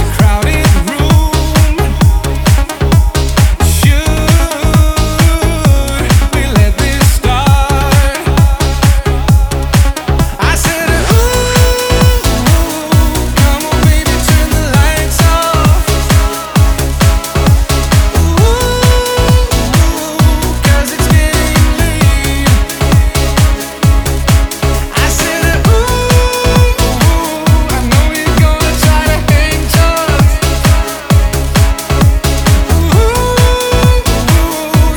Жанр: Хип-Хоп / Рэп / Танцевальные / Электроника
Dance, Electronic, Hip-Hop, Rap